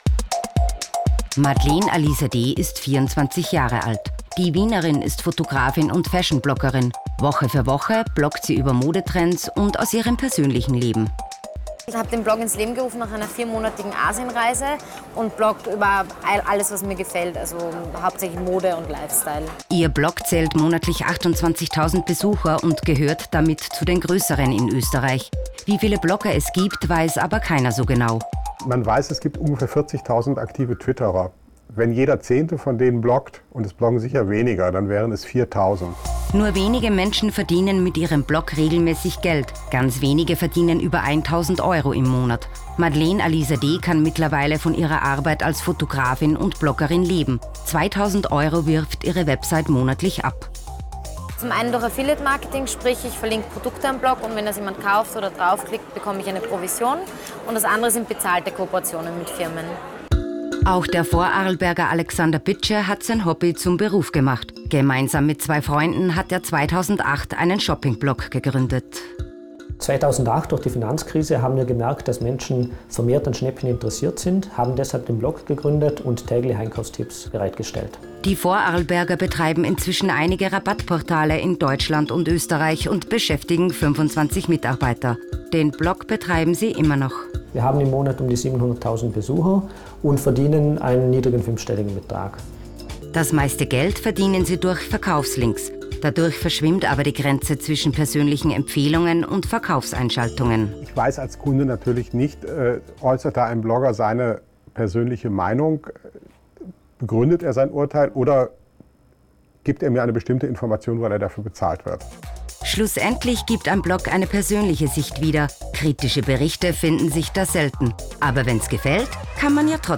Reportage – Blogger in Österreich
(Sprechercasting)